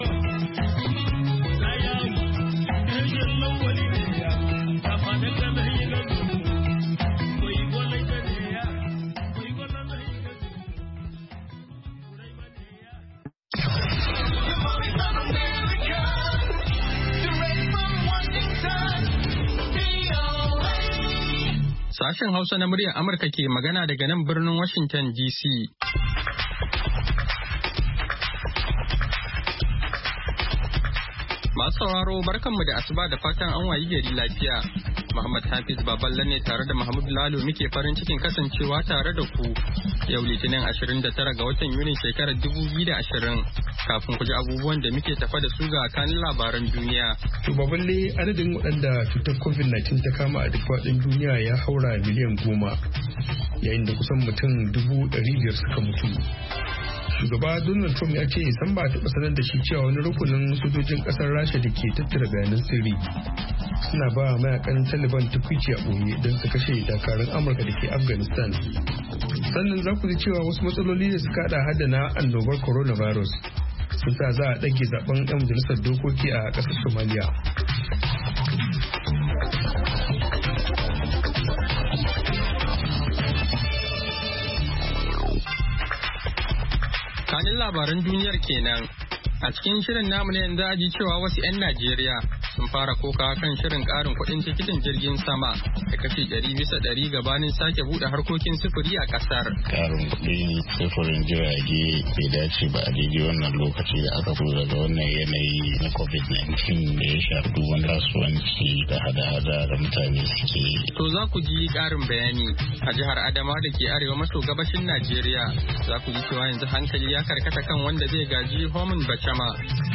Kullum da karfe 6 na safe agogon Najeriya da Nijar muna gabatar da labarai da rahotanni da dumi-duminsu, sannan mu na gabatar da wasu shirye-shirye kamar Noma da Lafiya Uwar Jiki.